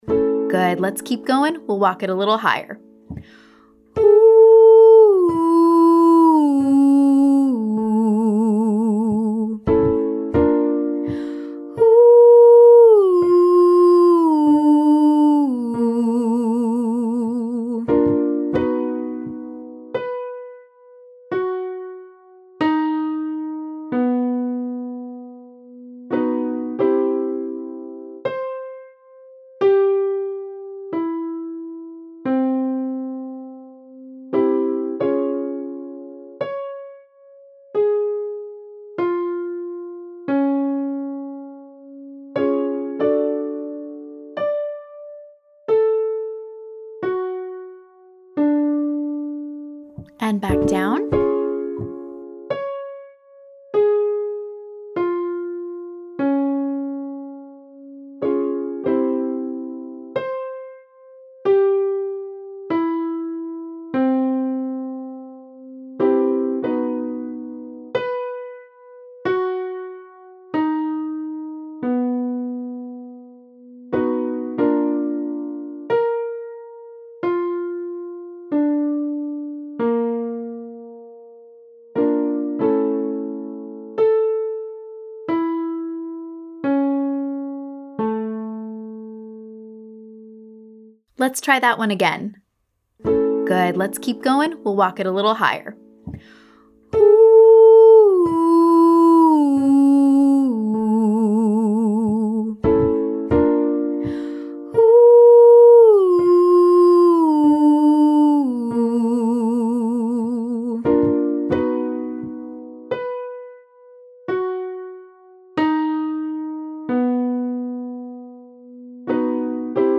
- Online Singing Lesson
Rapid 15151
Descending waterfall 8531